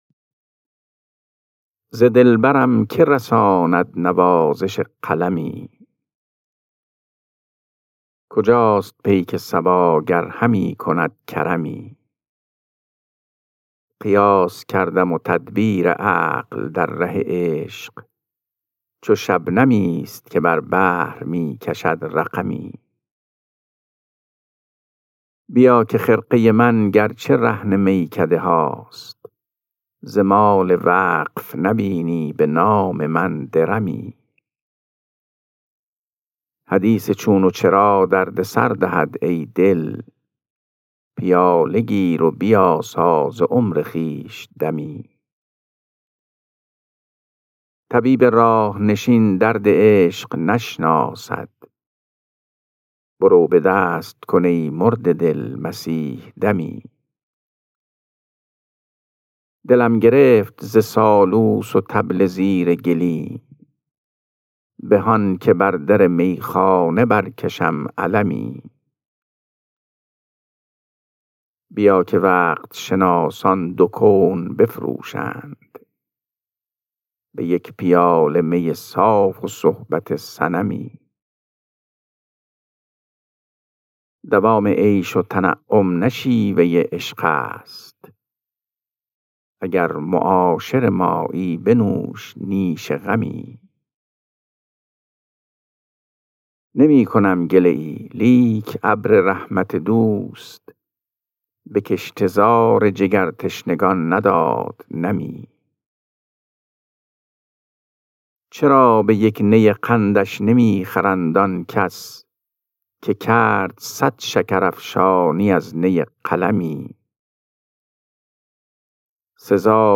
خوانش غزل شماره 471 دیوان حافظ